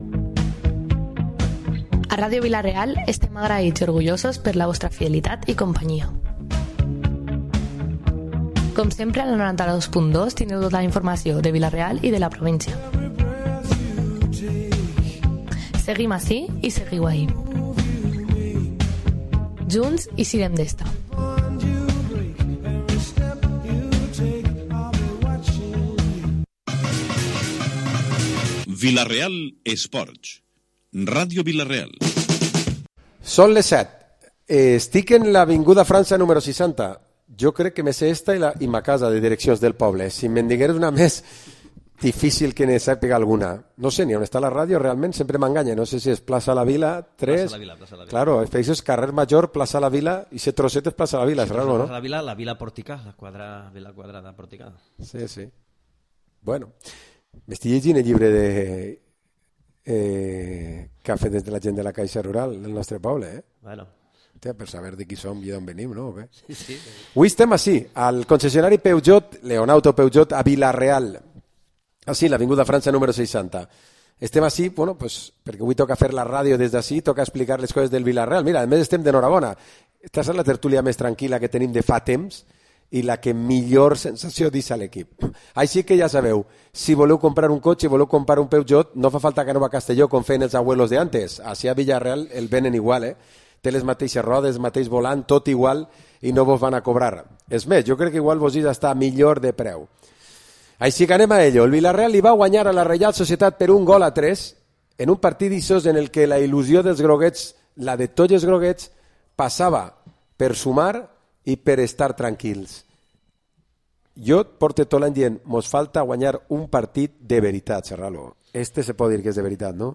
Programa esports tertúlia dilluns 26 de febrer